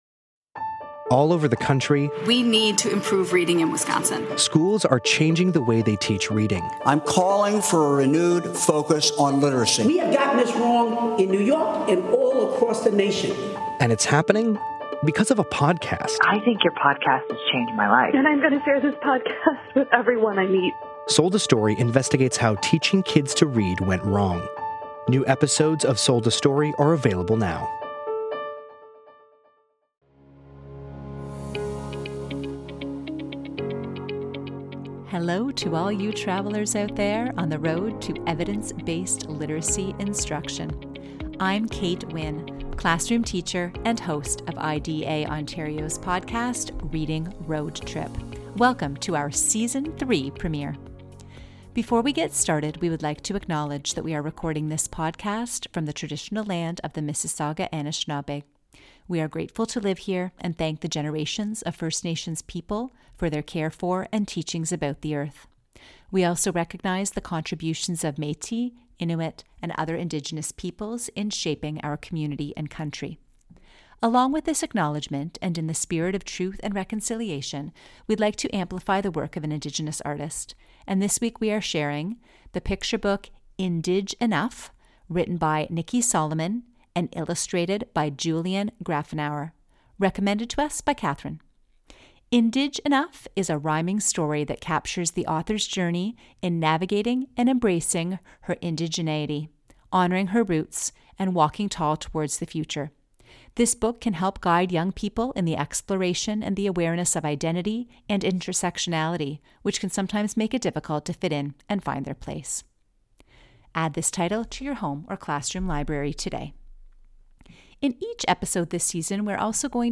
Their conversation is both thoughtful and lighthearted - we hope you will enjoy!